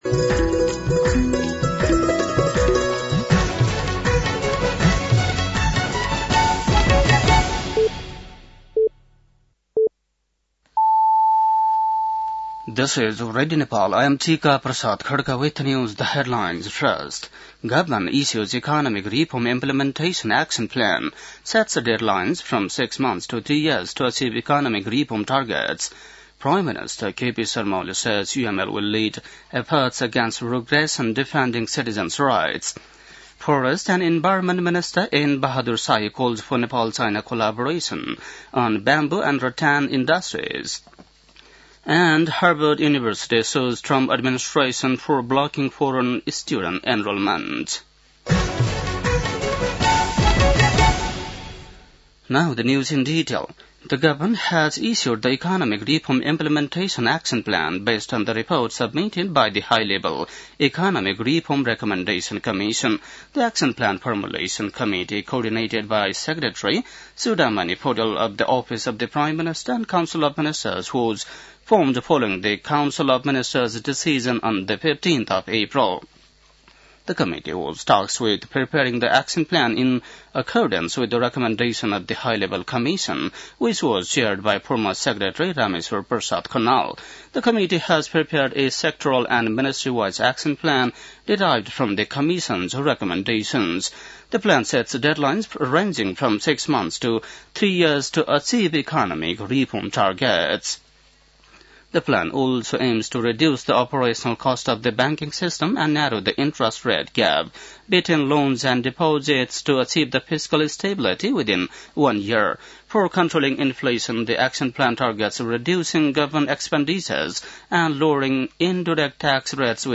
बेलुकी ८ बजेको अङ्ग्रेजी समाचार : ९ जेठ , २०८२
8-PM-English-NEWS-02-09.mp3